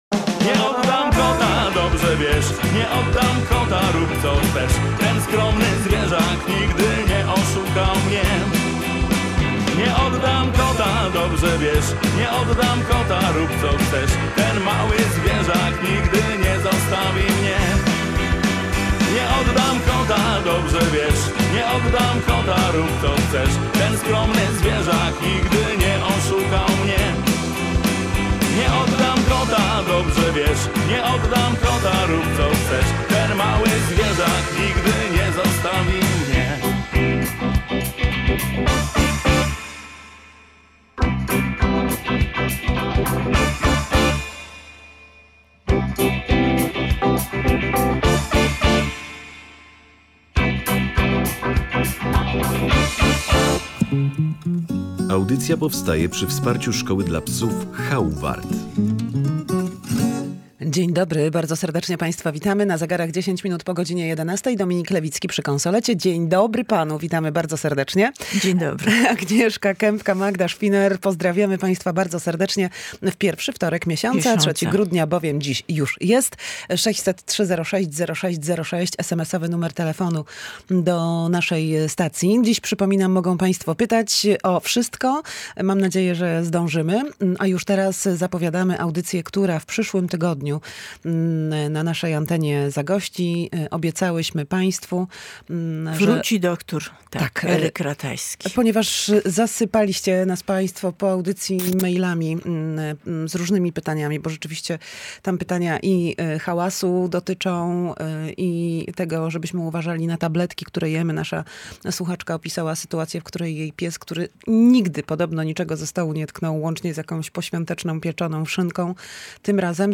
Odniosła się również do pytań słuchaczy o to, jak przygotować pupila na stres związany z sylwestrowym hałasem.